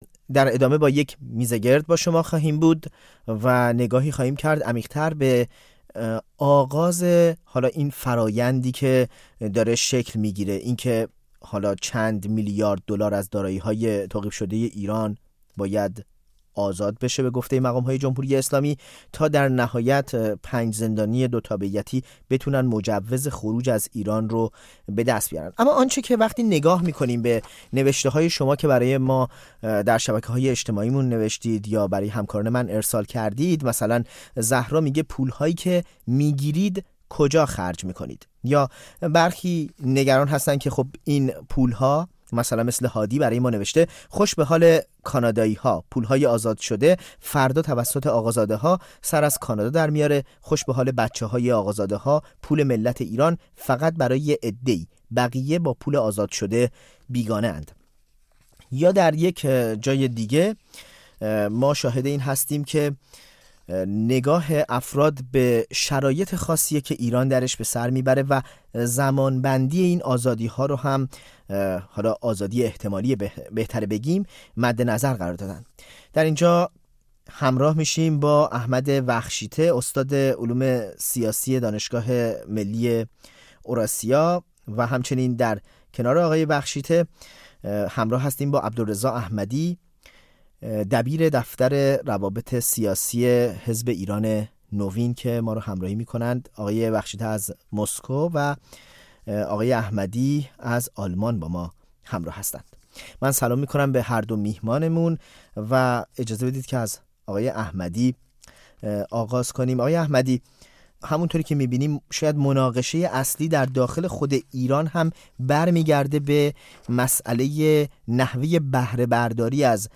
نگاهی به توافق ایران و آمریکا برای مبادله زندانیان دوتابعیتی در قبال آزاد شدن پول های بلوکه ایران و آزموده‌ها و آموزه‌ها در این باره دریک میزگرد.